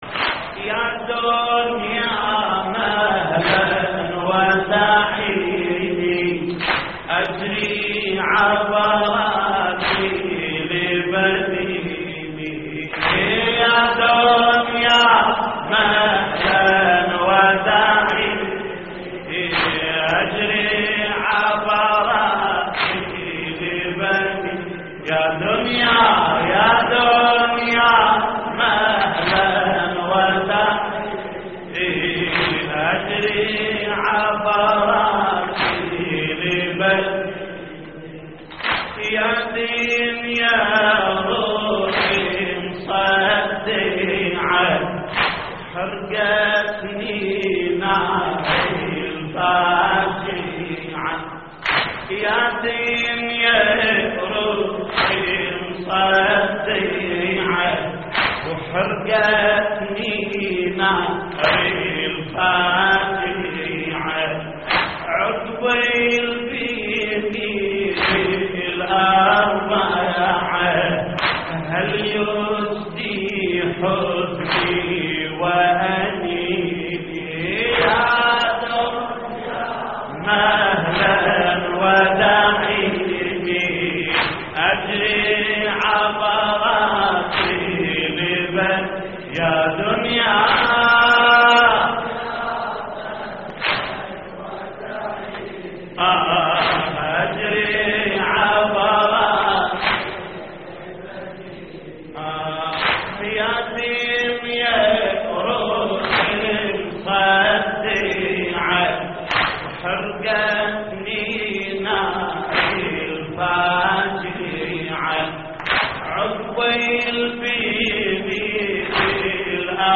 تحميل : يا دنيا مهلاً ودعيني أجري عبراتي لبنيني / الرادود جليل الكربلائي / اللطميات الحسينية / موقع يا حسين